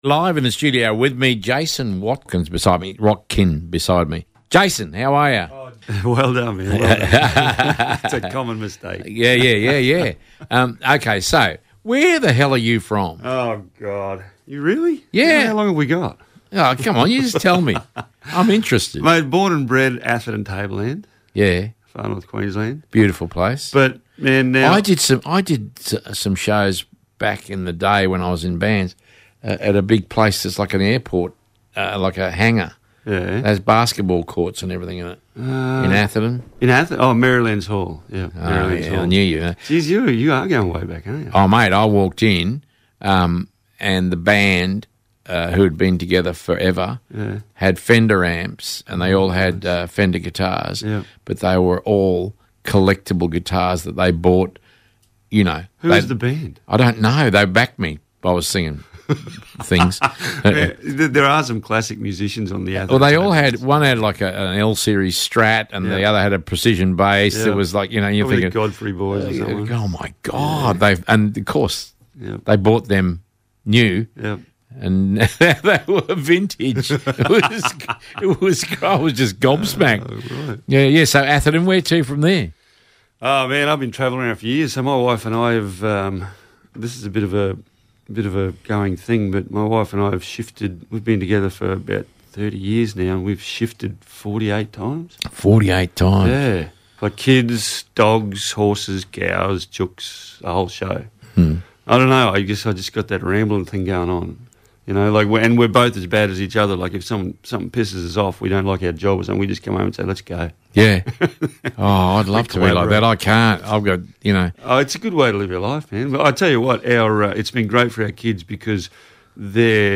A great chat!!